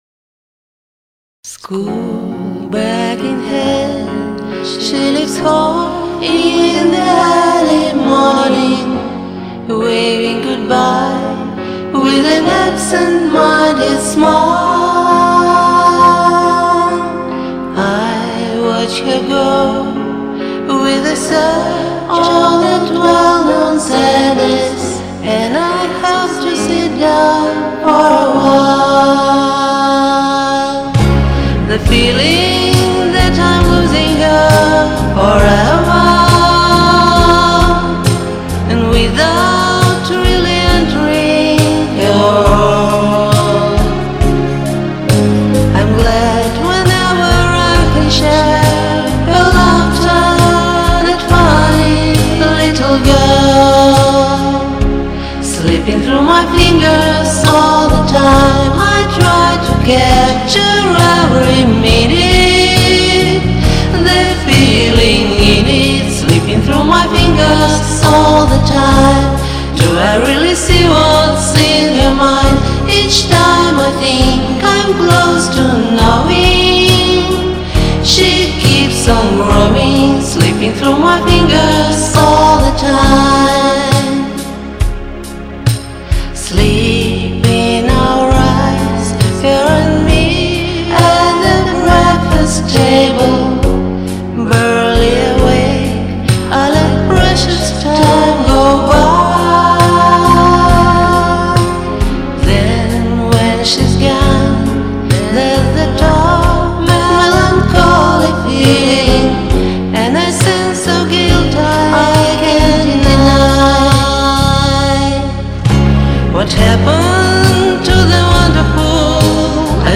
(здесь я со своими бэками)))